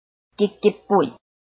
拼音查詢：【詔安腔】gid ~請點選不同聲調拼音聽聽看!(例字漢字部分屬參考性質)